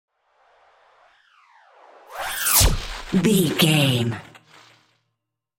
Whoosh sci fi disappear electricity
Sound Effects
futuristic
whoosh